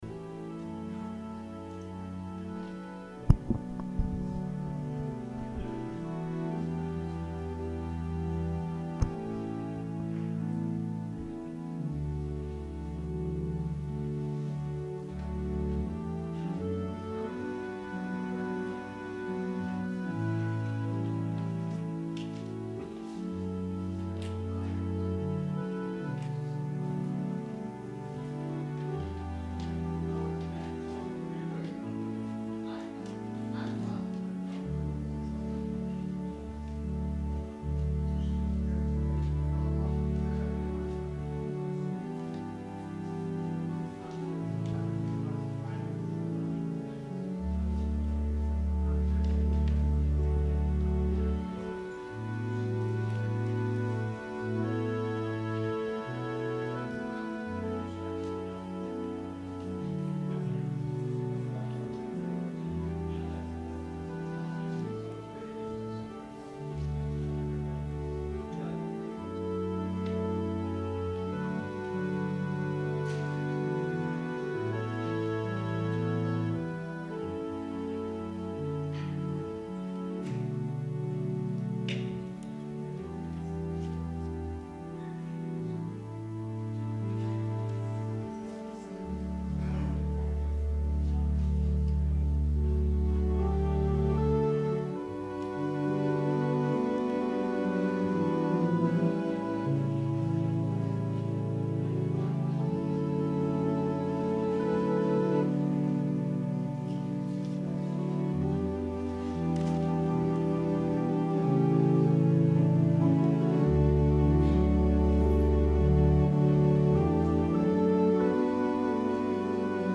Sermon - February 24, 2019